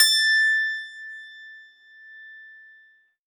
53s-pno20-A4.aif